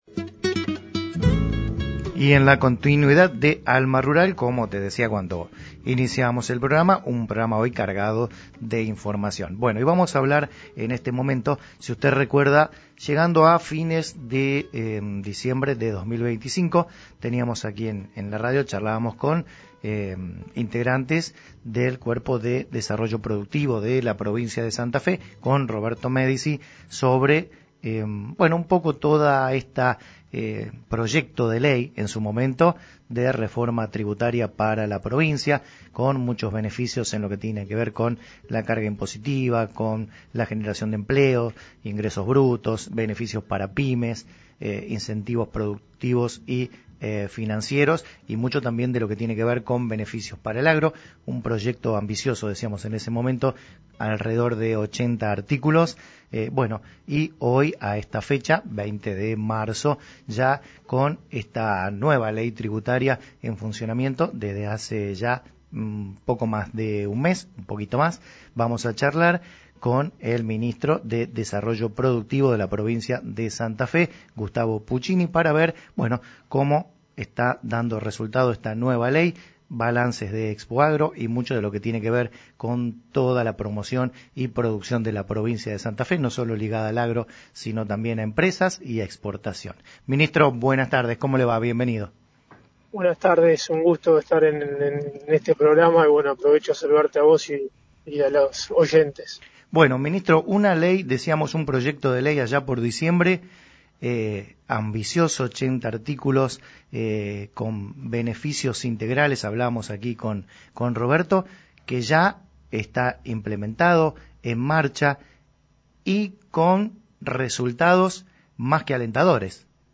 En una reciente entrevista realizada en el programa Alma Rural de CDG24 Radio, el Ministro de Desarrollo Productivo de Santa Fe, Gustavo Puccini, analizó el impacto de las políticas provinciales y destacó que la actual gestión busca ser un aliado directo del sector privado.